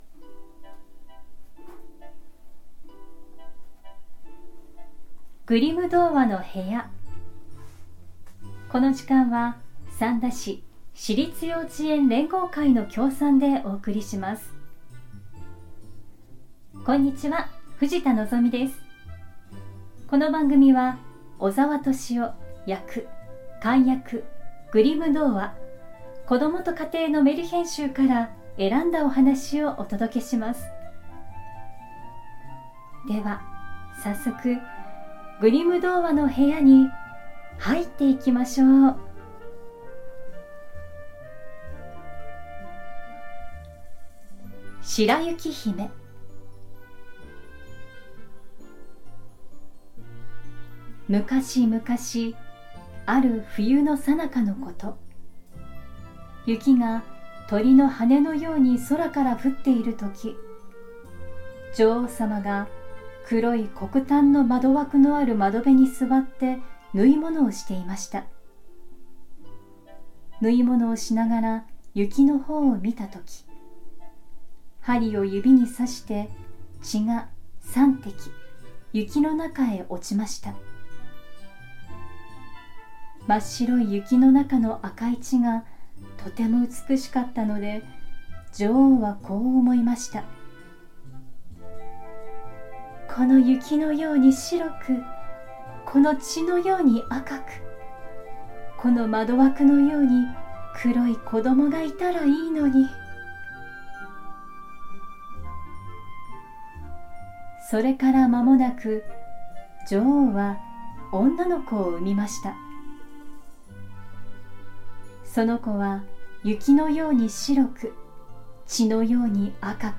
グリム兄弟によって集められたメルヒェン（昔話）を、翻訳そのままに読み聞かせします📖 今回お届けするのは『白雪姫』。